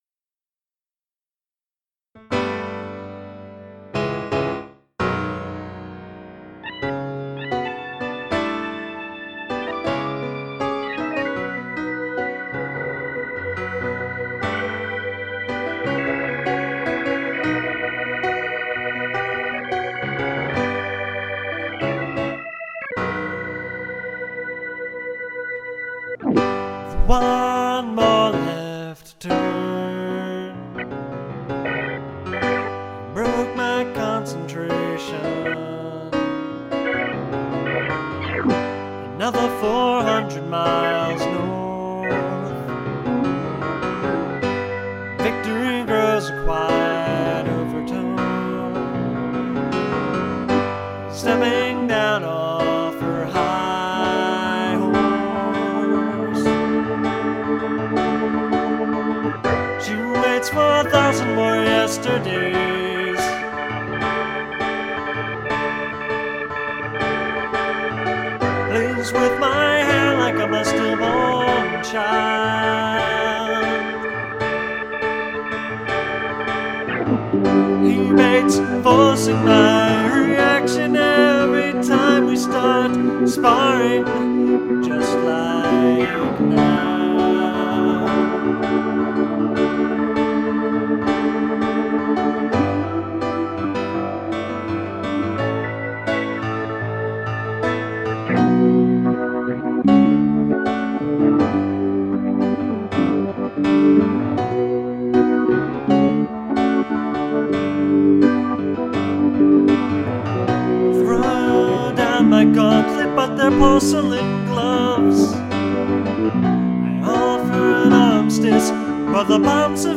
Category Archives: piano
Anyhow, this song is about a lot of things.  The demo is somewhat rough and bear, but there’s a part of me that hears it as a prog rock anthem, just because that would be fun.